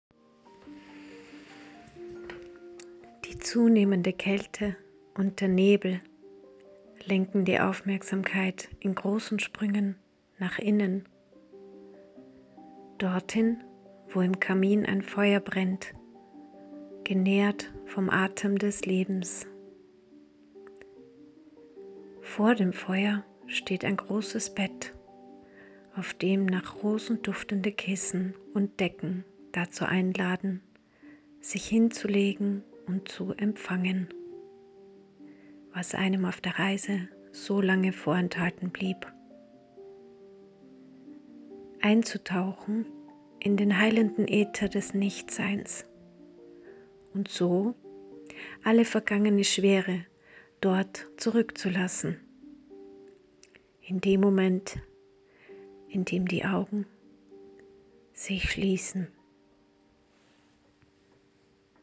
Gedicht